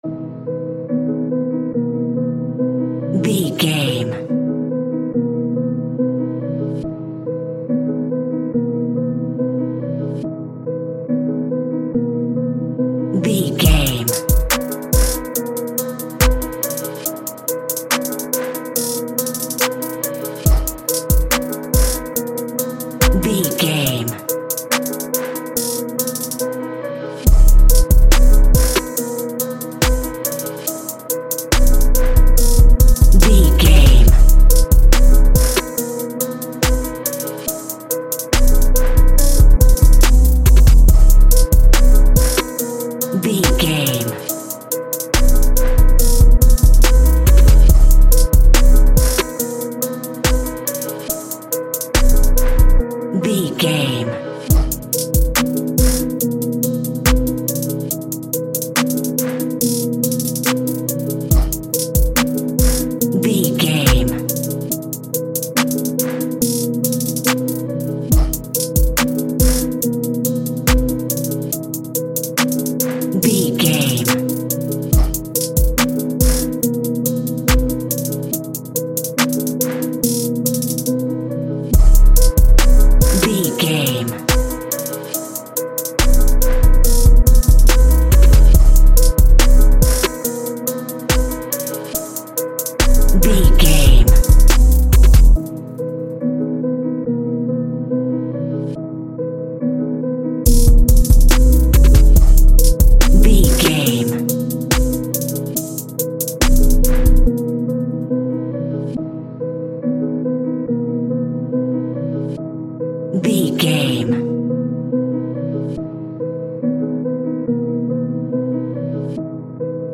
Ionian/Major
aggressive
intense
driving
energetic
dark
drums